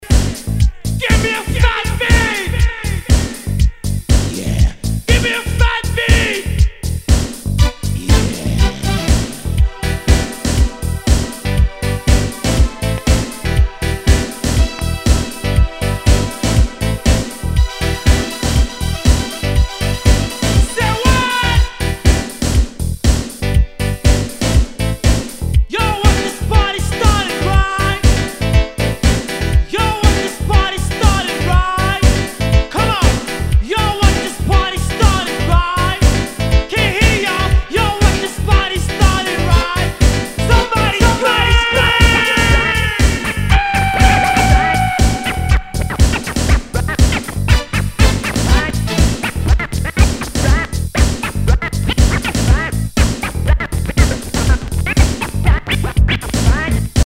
HOUSE/TECHNO/ELECTRO
ナイス！ハウス・クラシック！
全体にチリノイズが入ります